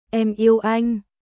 Em yêu anhエム イェウ アイン私（女）はあなた（男）を愛しています